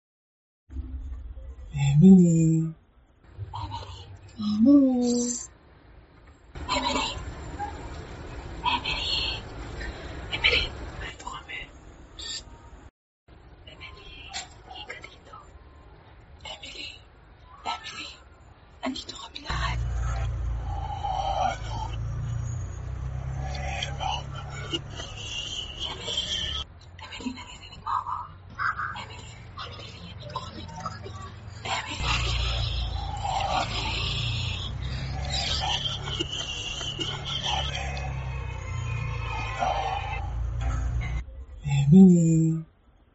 Pst Psst Sound Button - Free Download & Play